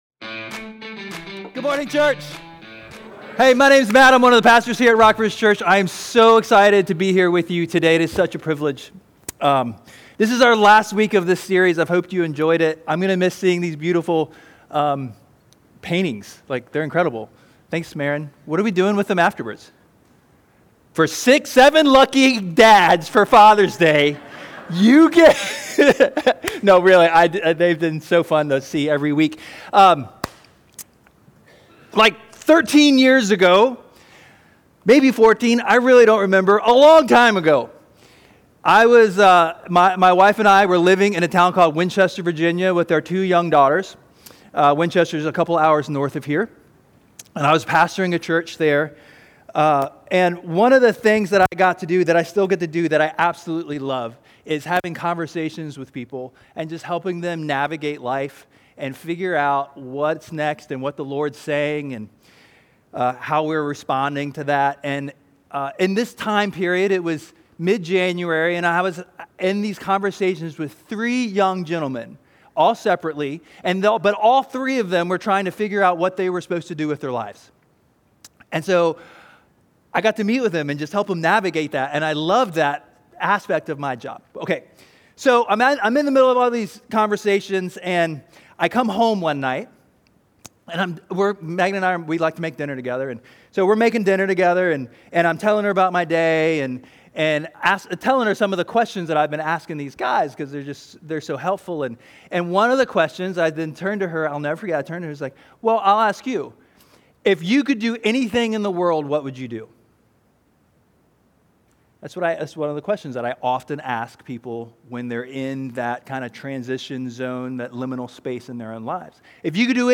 6-15-25 SermonOnly.mp3